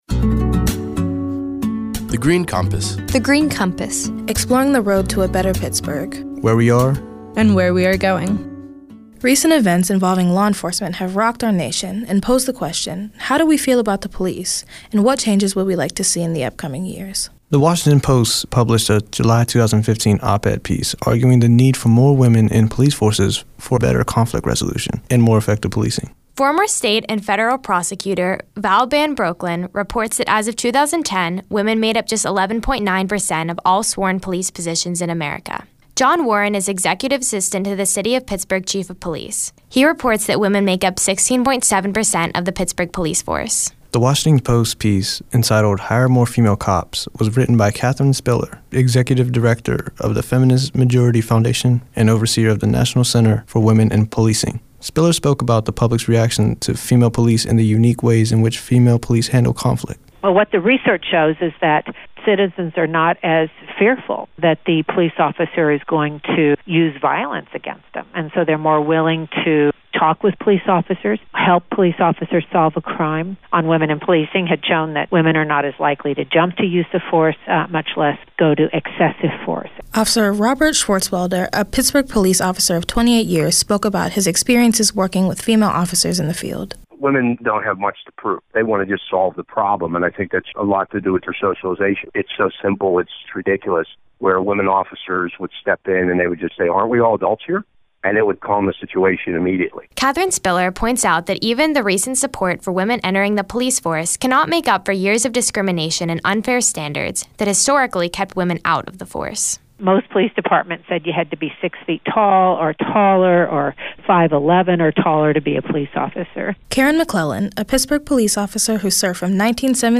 In July 2015, twenty-one recent high-school graduates created these radio features while serving as Summer Interns at The Heinz Endowments.